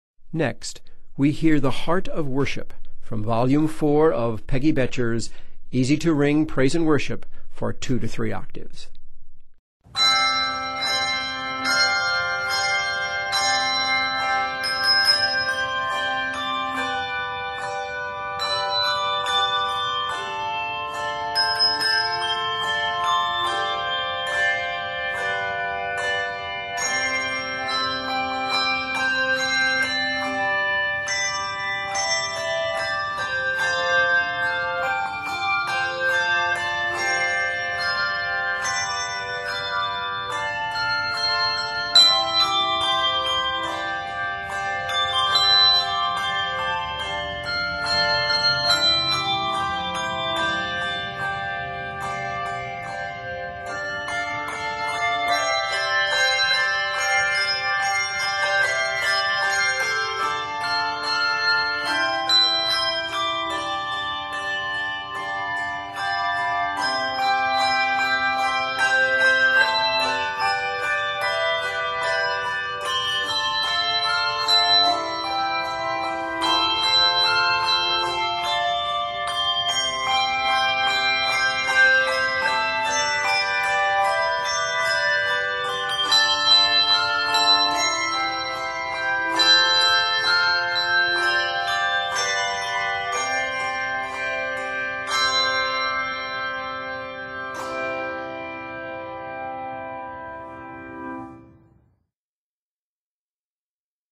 Octaves: 2-5